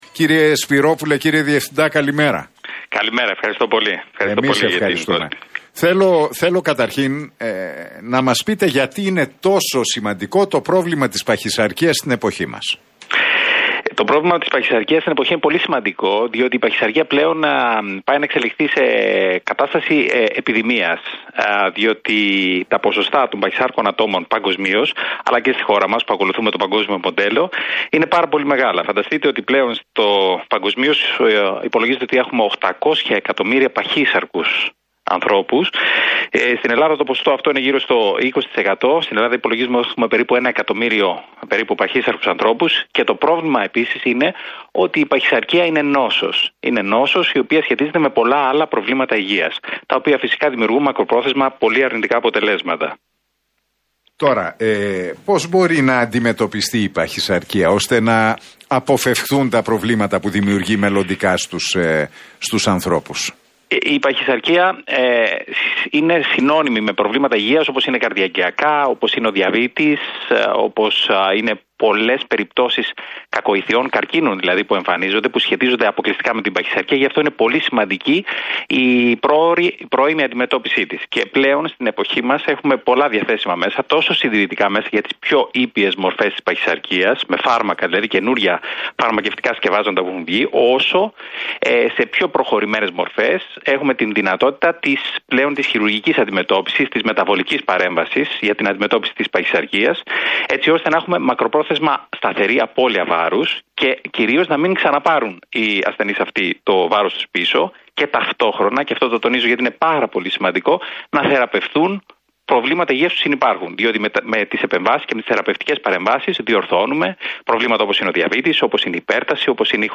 μιλώντας στην εκπομπή του Νίκου Χατζηνικολάου στον Realfm 97,8 δήλωσε ότι “η παχυσαρκία πλέον πάει να εξελιχθεί σε κατάσταση επιδημίας.